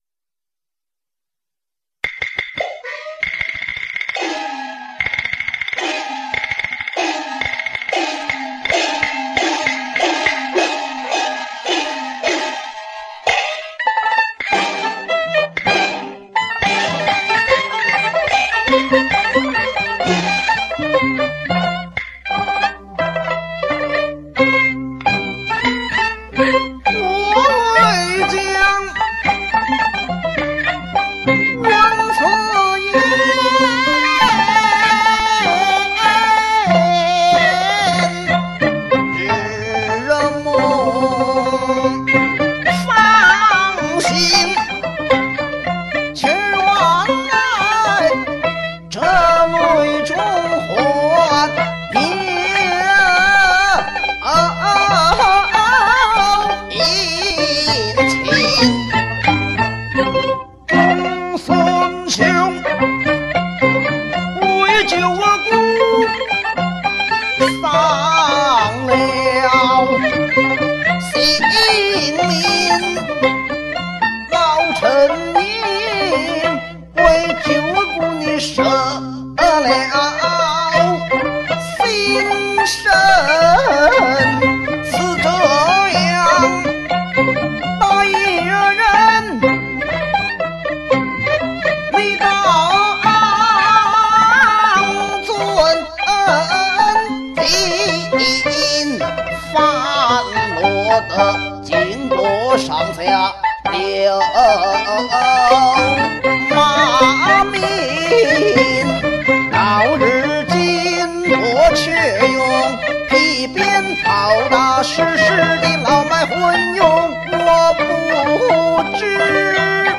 反串【赵氏孤儿】花脸